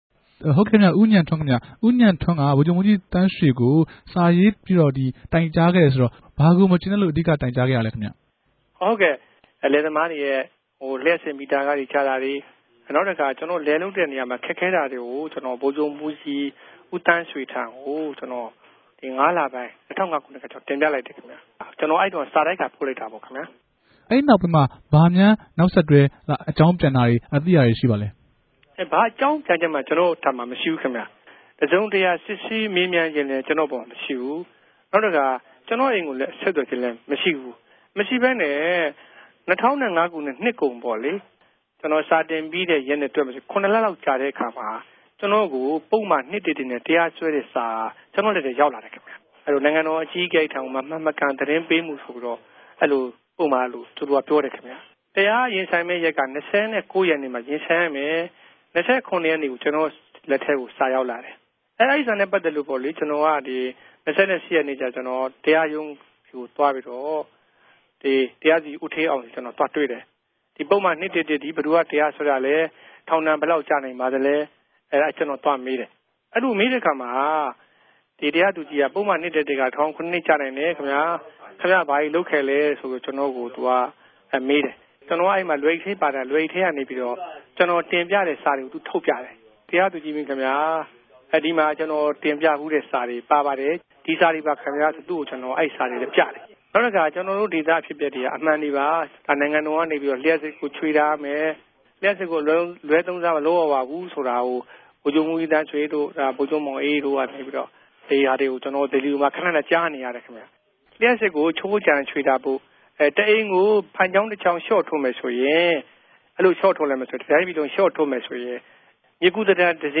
ဒီမေးူမန်းခဵက်ကို ၂ ပိုင်းခြဲ ထုတ်လြင့်ခဲ့တာူဖစ်္ဘပီး ပထမပိုင်းနဲႛ ဒုတိယပိုင်းကို နားထောငိံိုင်ုကပၝတယ်။